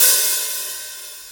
061 - HH-4O.wav